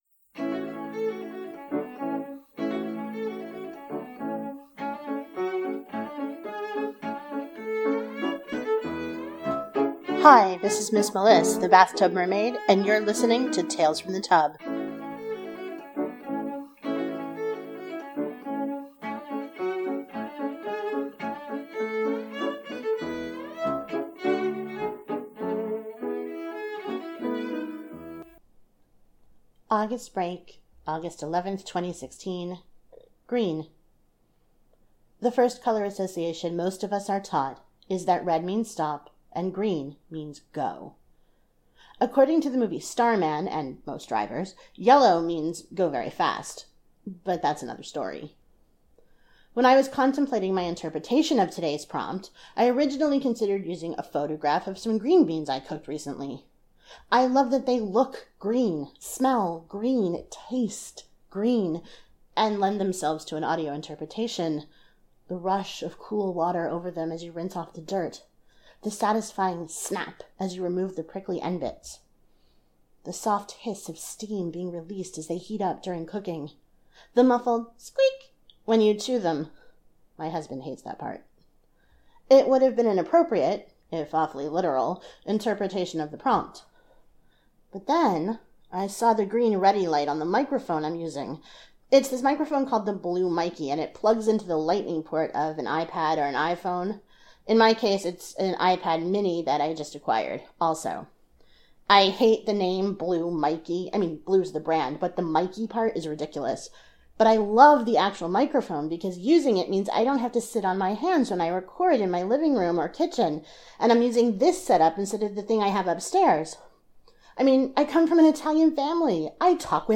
• Music used for the opening and closing is David Popper’s “Village Song” as performed by Cello Journey.